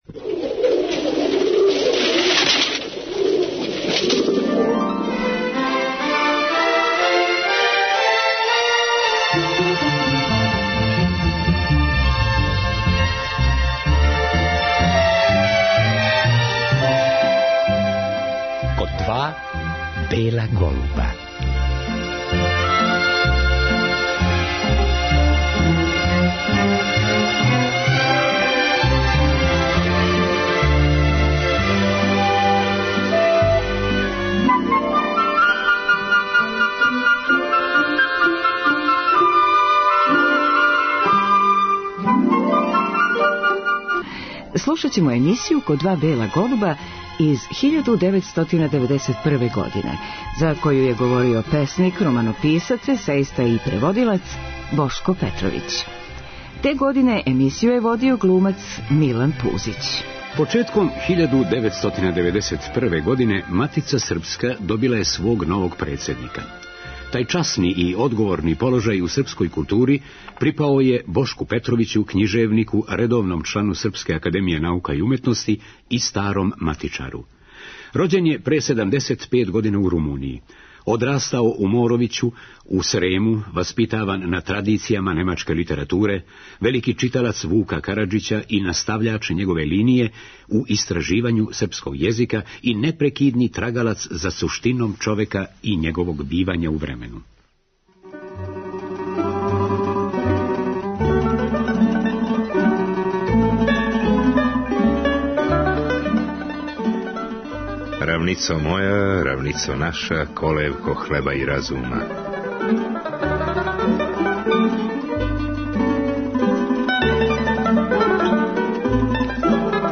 Разговор са академиком је вођен 1991.године некако после његовог избора за председника Матице српске.